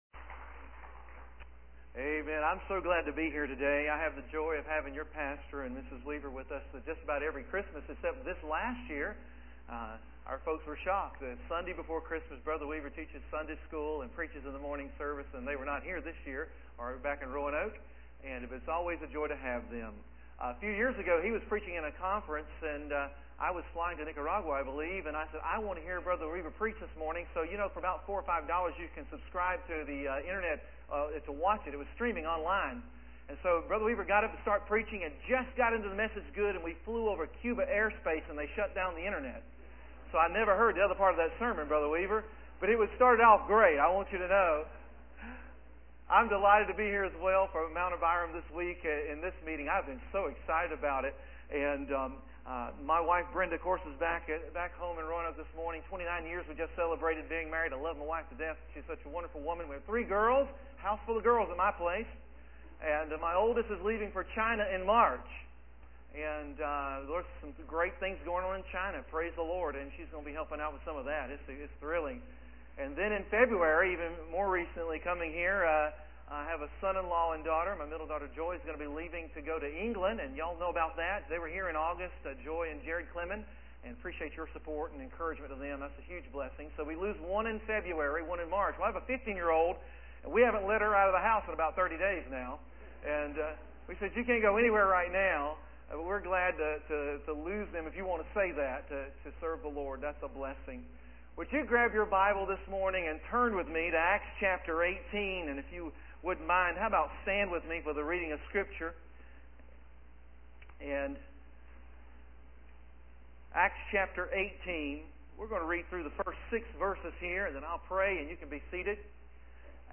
Sermon Audio Every Layperson